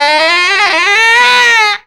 OB SCALE.wav